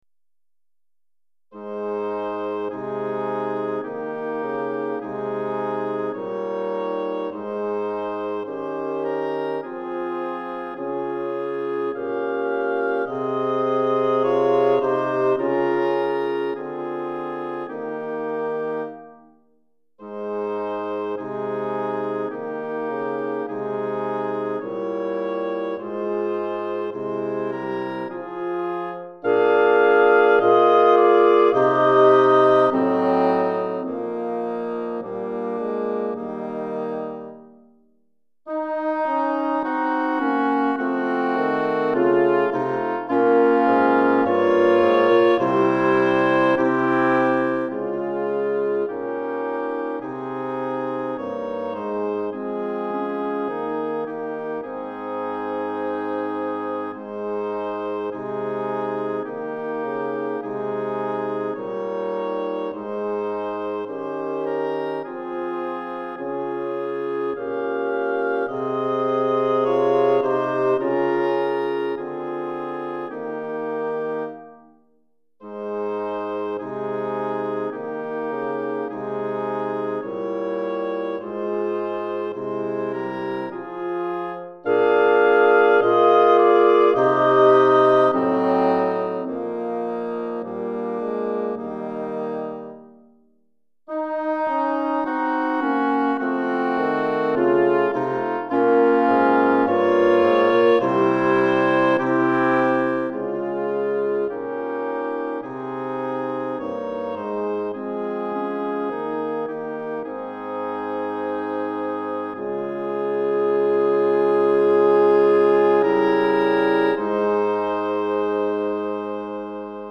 Hautbois 1 Clarinette en Sib 1 Cor en Fa 1 Basson